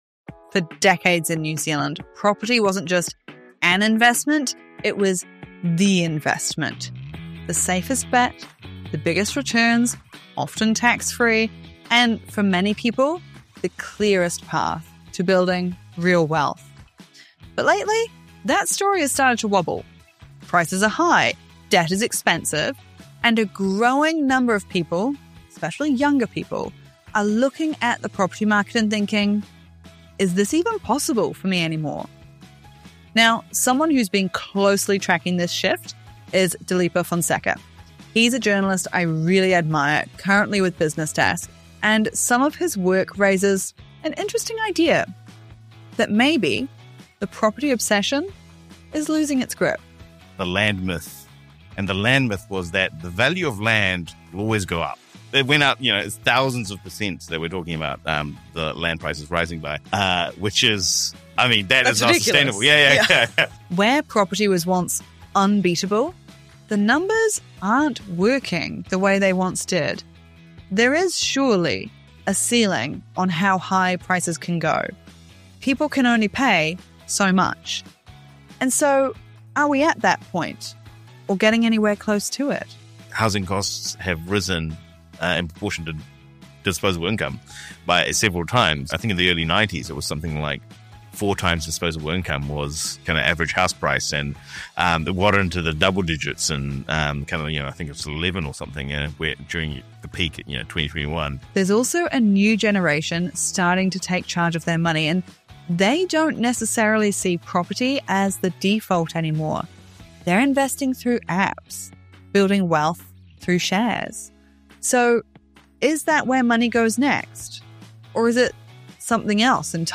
If you’ve ever wondered whether buying property is still the smartest investment strategy, or whether it’s time to rethink the traditional path to wealth, this conversation will challenge your assumptions.